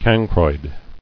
[can·croid]